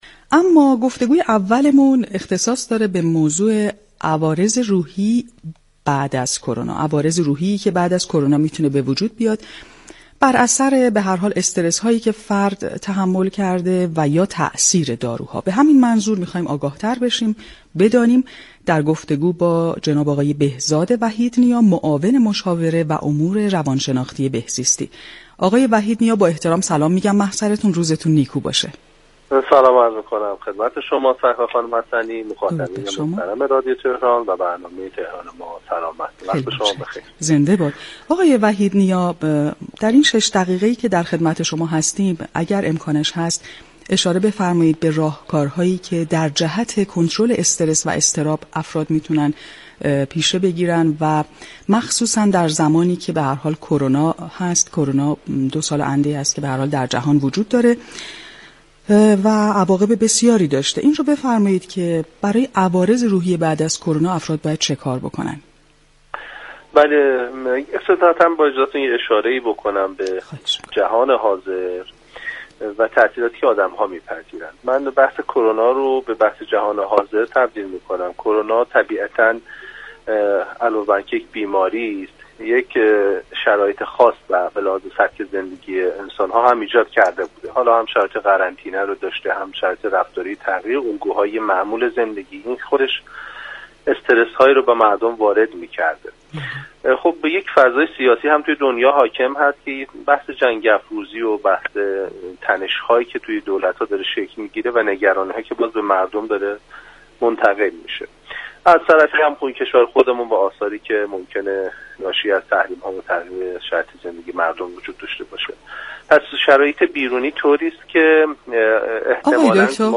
به گزارش پایگاه اطلاع‌رسانی رادیو تهران، بهزاد وحیدنیا معاون مشاوره و امور روانشناختی سازمان بهزیستی در گفتگو با تهران ما،سلامت رادیو تهران در پاسخ به این پرسش كه بیماران مبتلا به كرونا چگونه با عوارض احتمالی پس از ابتلا به این بیماری مقابله كنند؟